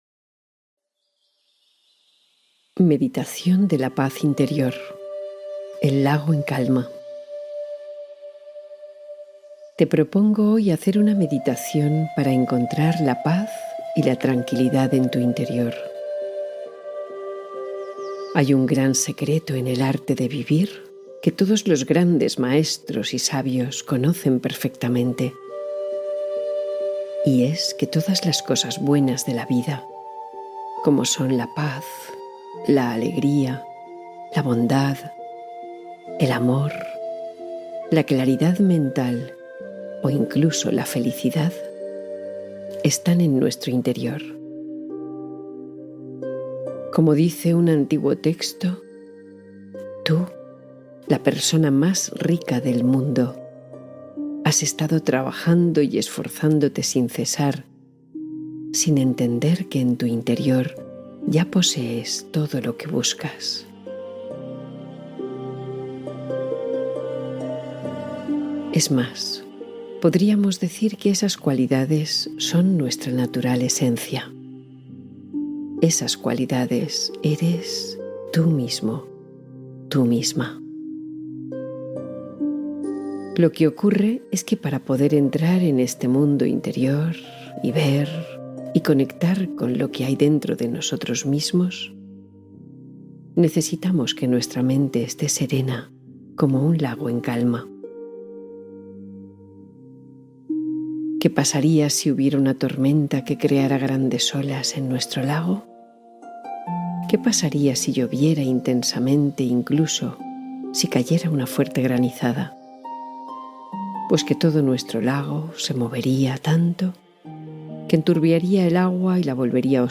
El Lago en Calma: Meditación Para Serenar la Mente
Todas las emisiones de Meditación para la Ansiedad comienzan con los anuncios al inicio porque queremos que, una vez entres, nada interrumpa tu respiración, tu emoción, tu silencio ni ese pequeño espacio donde te reconoces.